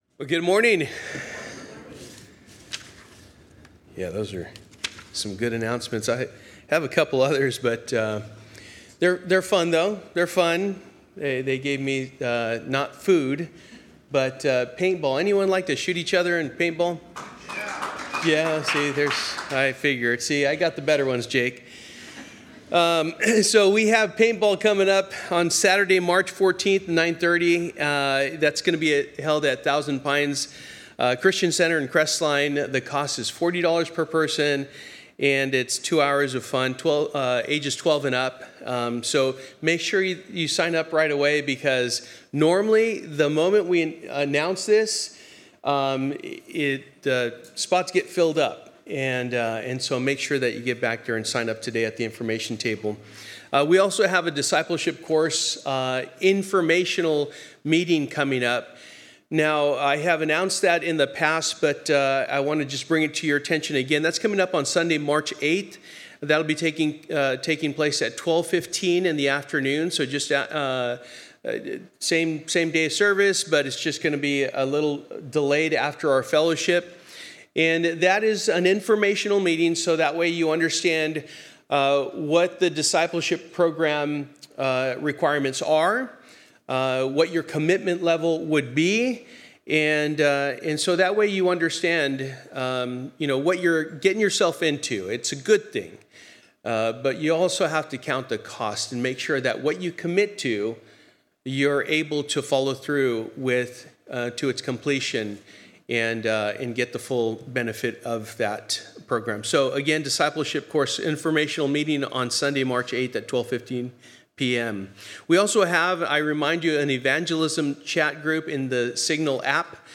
1 Peter Passage: 1 Peter 3:8-22 Service: Sunday Morning « Day 3